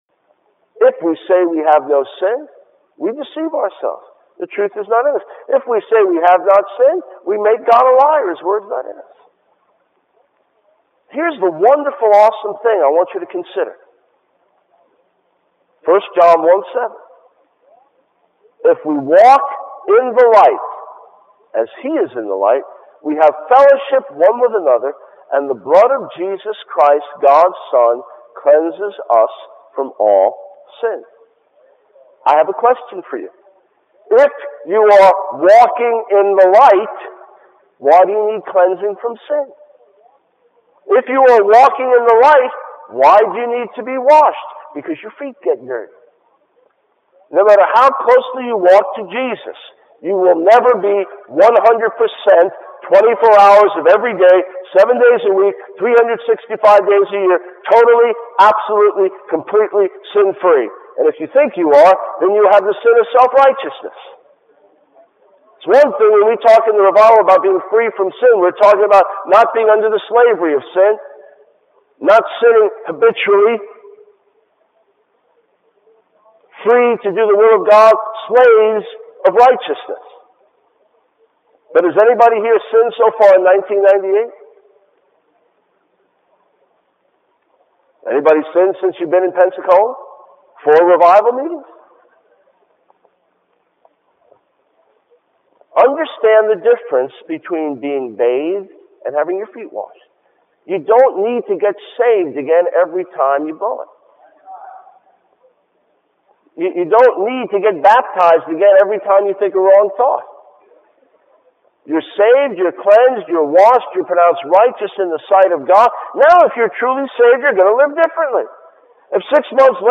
In this sermon, the speaker emphasizes the importance of living a holy and righteous life as a child of God. He warns against being entertained by sinful habits and urges listeners to deal with recurring sinful patterns ruthlessly.